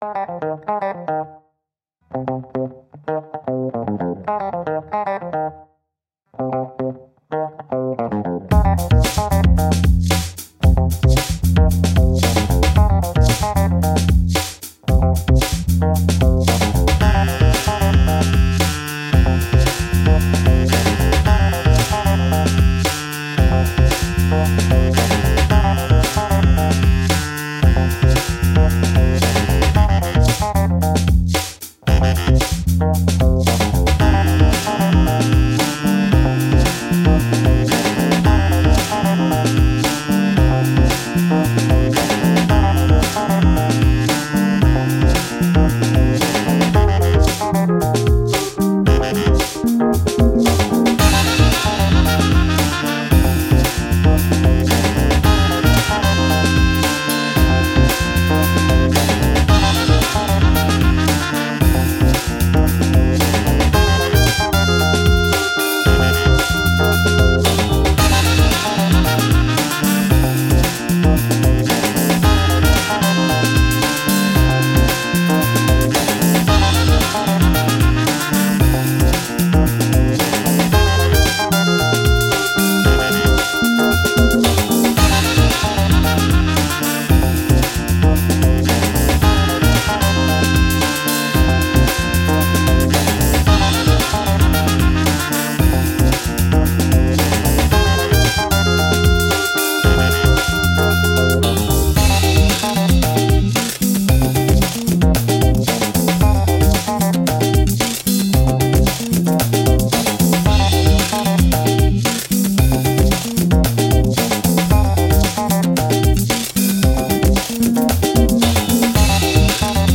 Afrobeat, Breakbeat, Upbeat, Fun